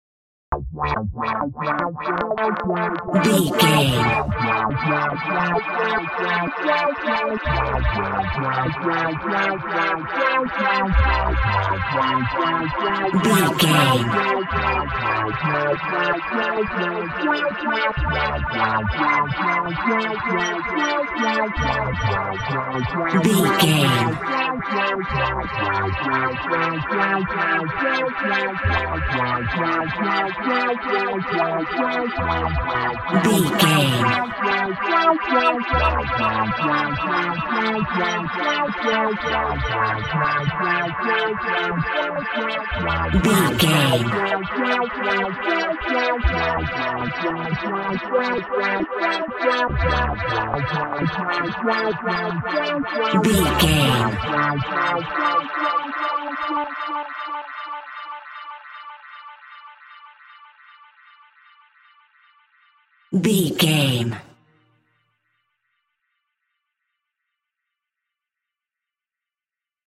In-crescendo
Thriller
Aeolian/Minor
ominous
dark
haunting
eerie
Horror synth
Horror Ambience
electronics
synthesizer